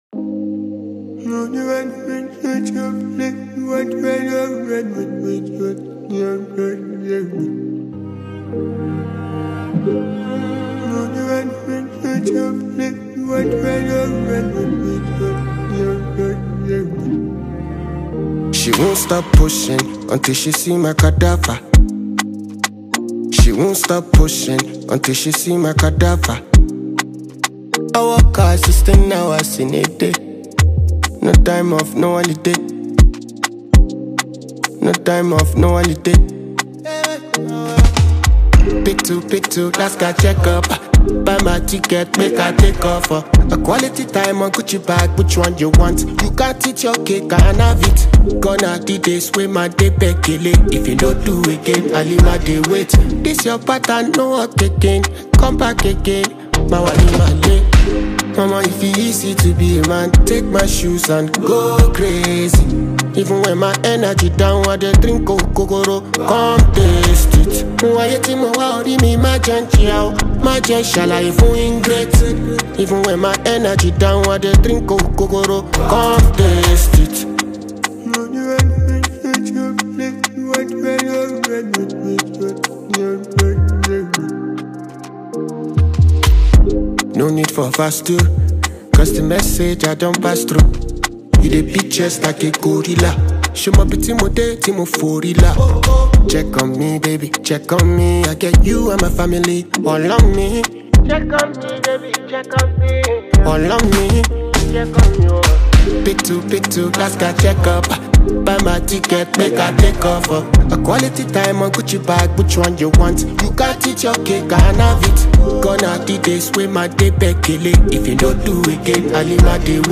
naija Afrobeat song